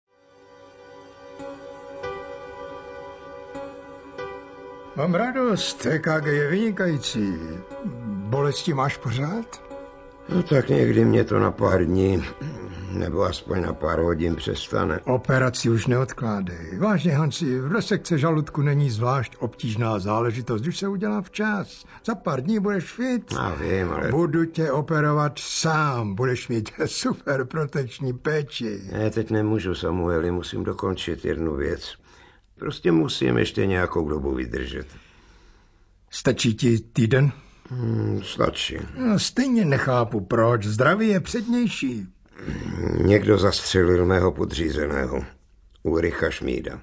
Rozhlasová nahrávka z roku 1994.
Vypočujte si ukážku audioknihy